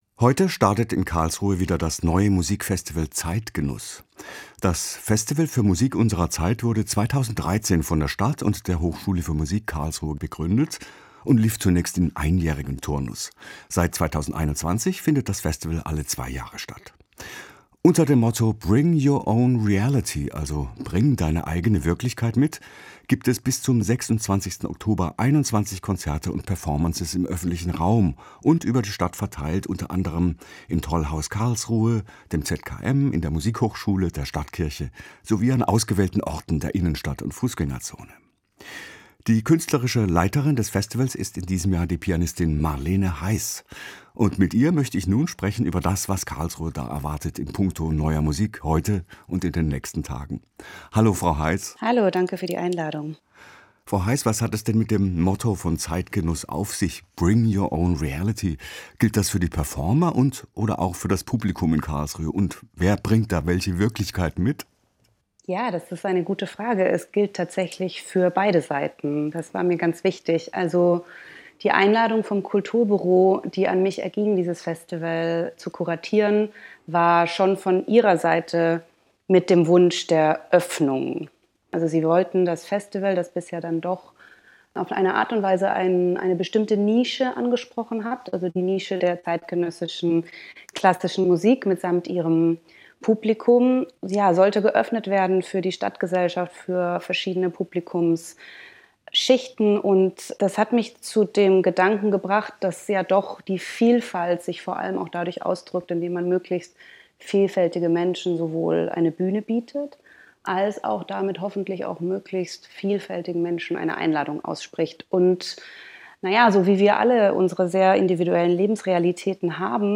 Musikgespräch
Interview mit